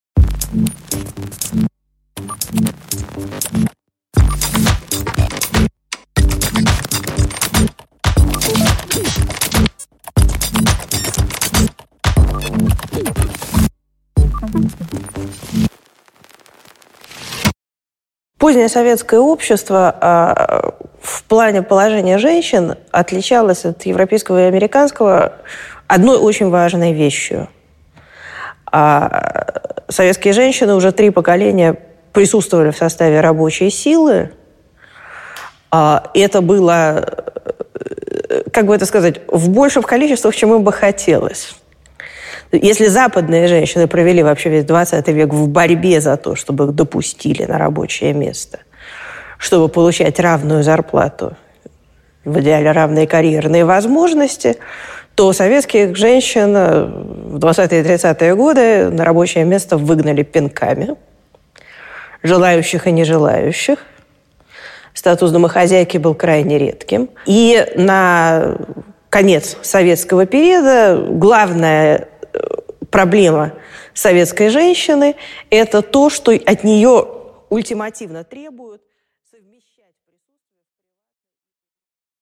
Аудиокнига Гендерные роли в постсоветском обществе | Библиотека аудиокниг